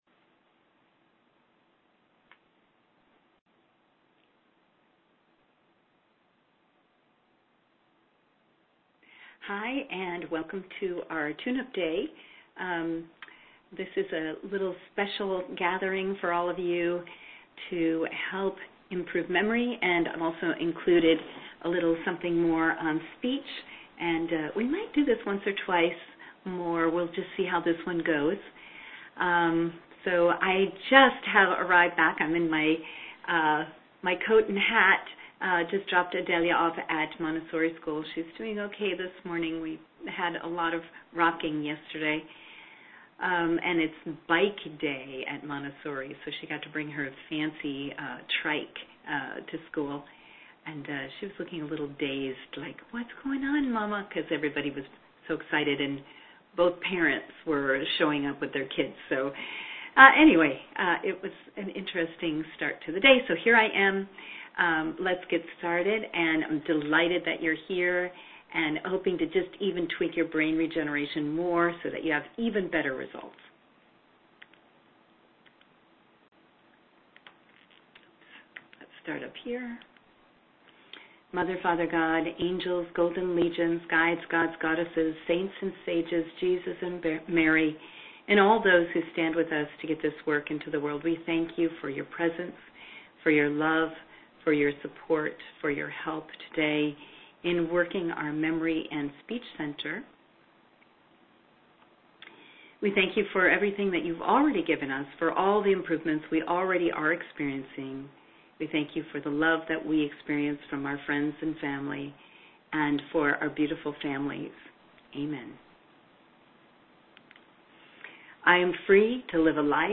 Yes plan a vacation soon, but enjoy this brief meditation to maintain the joy in your step and the twinkle in your eye when time is of the essence.